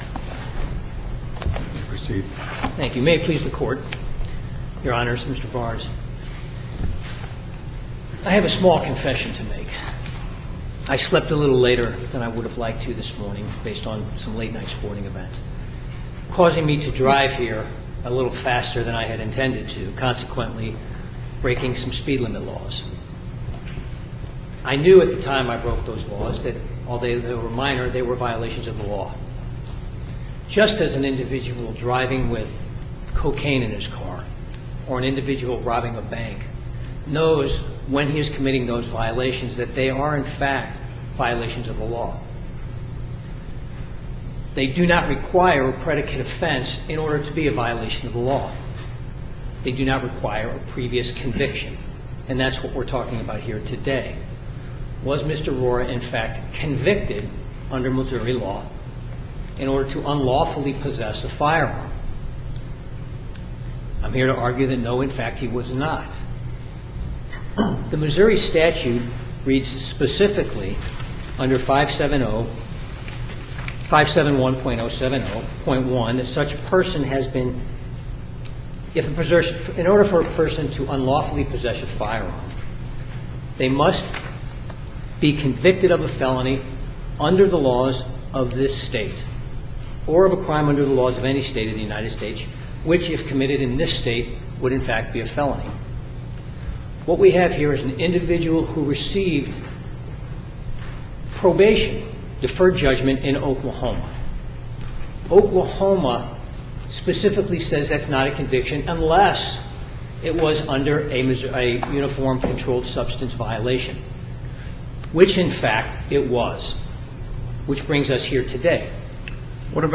MP3 audio file of oral arguments in SC96731